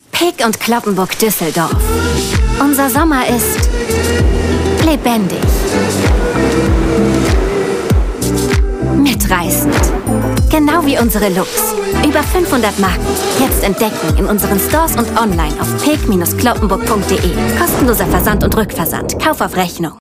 Sprachproben
Female